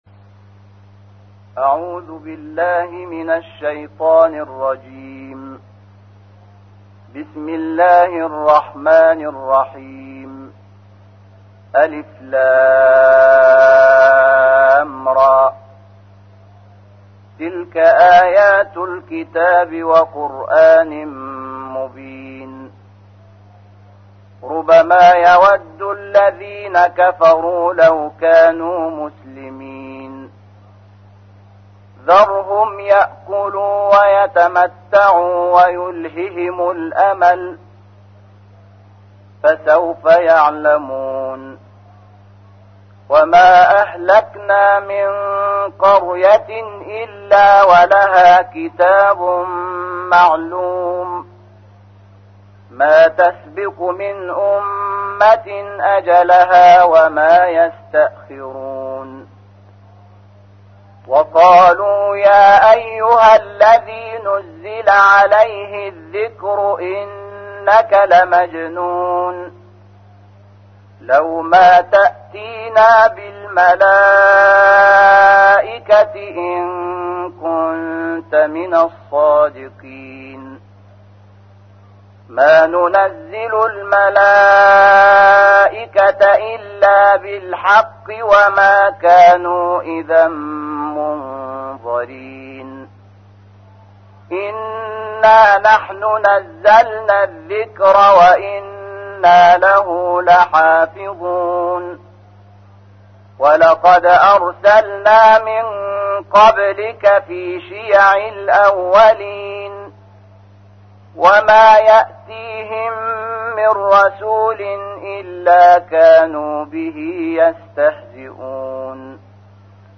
تحميل : 15. سورة الحجر / القارئ شحات محمد انور / القرآن الكريم / موقع يا حسين